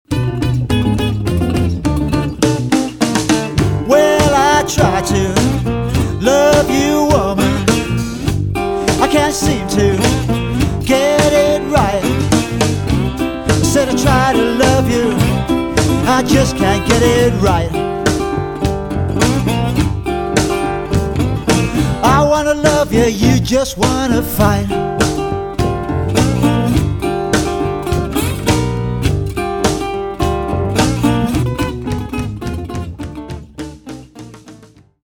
Recorded at RAMROD studio in Sydney.
fretless bass
drums